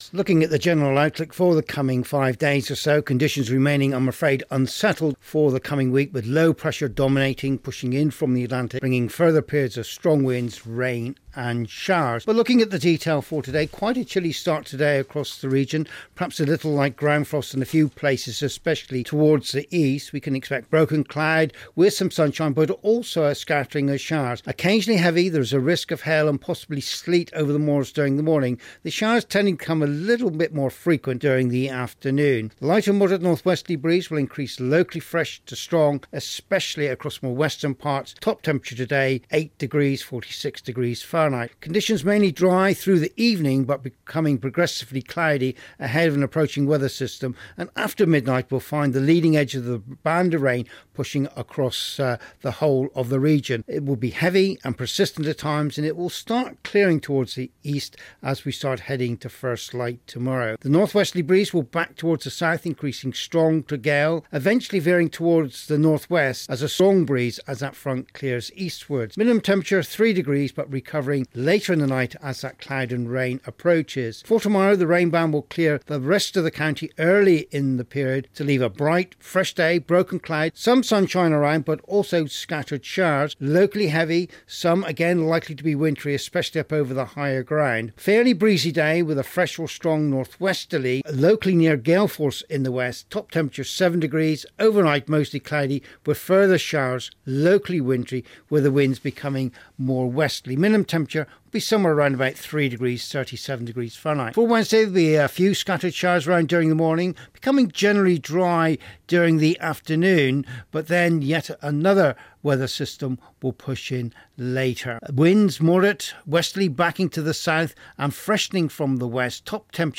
5 Day weather forecast for Cornwall and the Isle of Scilly